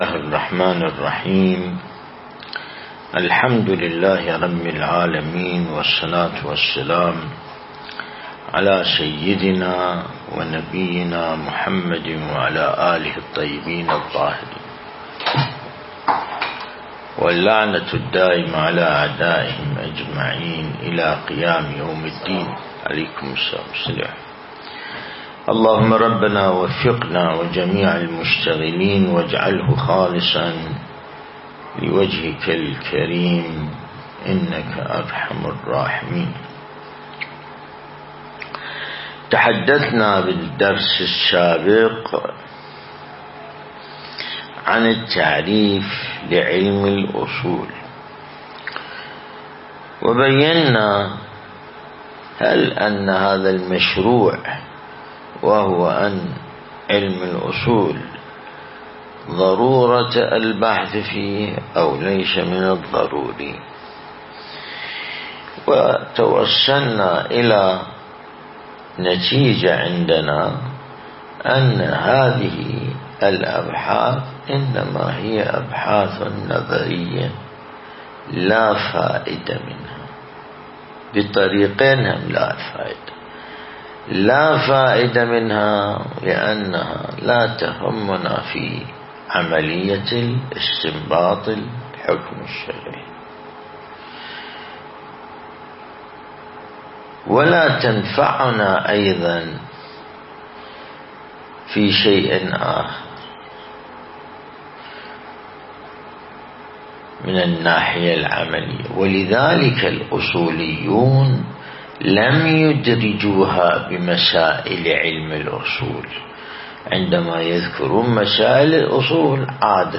درس البحث الخارج الأصول (3)